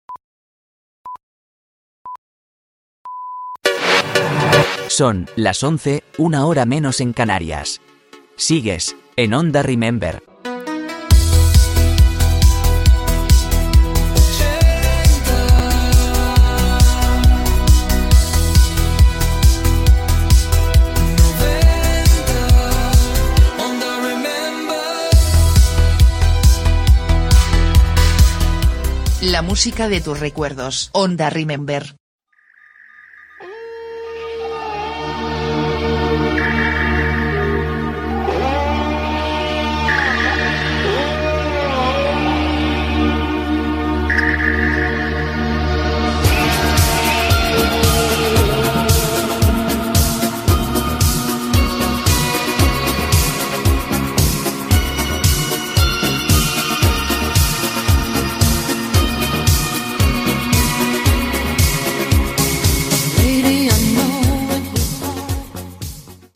Hora, identificació i tema musical.
Musical